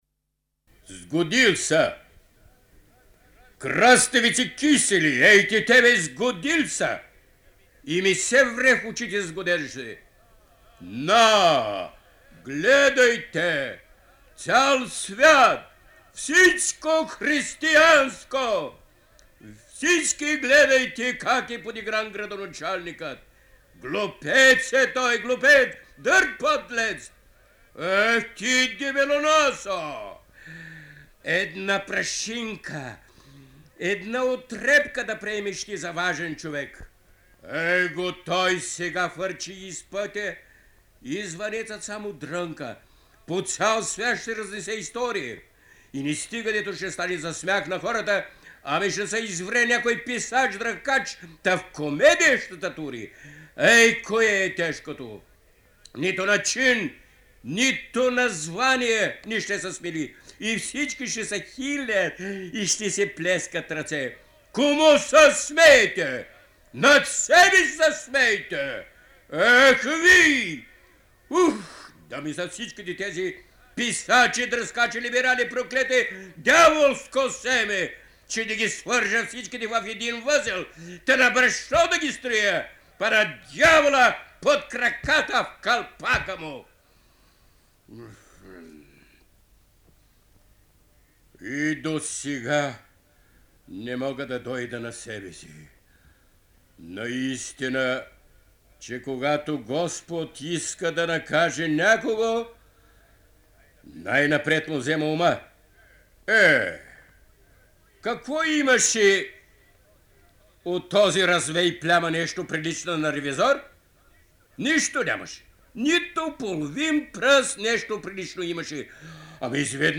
Той участва като актьор и режисьор в първото представяне на комедията на руския класик на българска сцена през далечната 1904 година, като запис от неговото превъплъщение в ролята на Градоначалника е съхранен в Златния фонд на БНР:
III4_Monolog-iz-Revizor.mp3